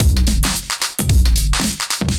OTG_DuoSwingMixD_110b.wav